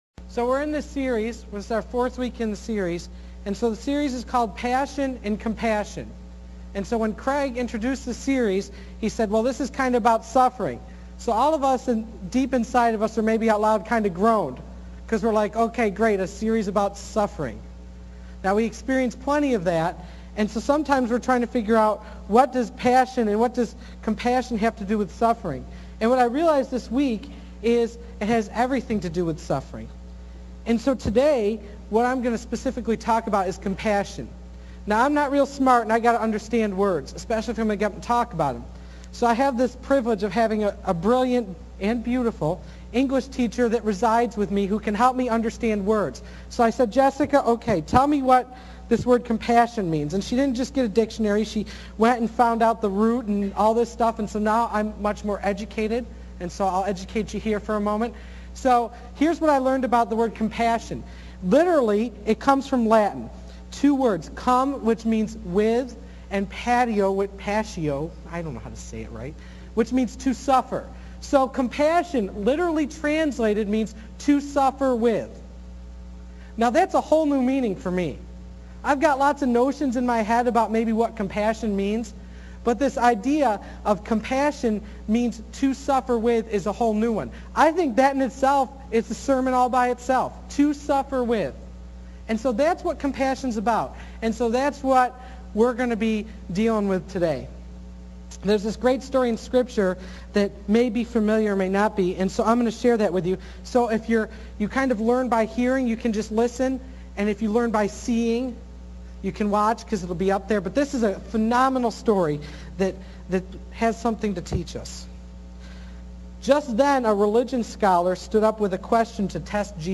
Passion and Compassion – Sermon Site